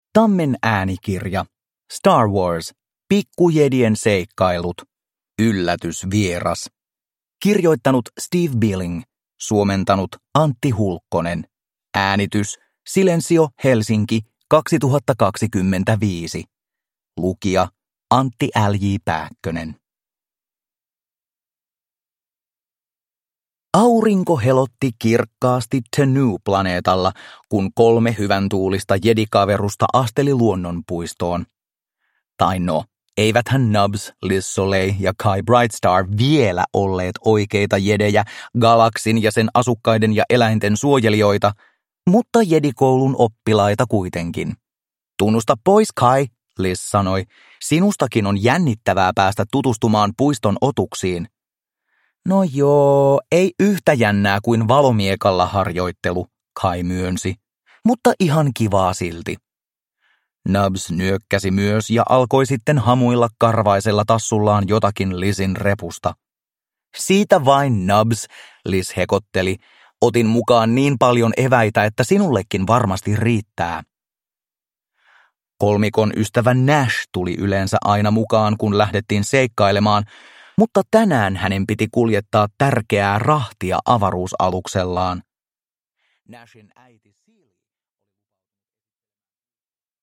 Star Wars. Pikkujedien seikkailut. Yllätysvieras – Ljudbok